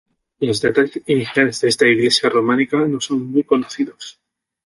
Pronunciado como (IPA) /konoˈθidos/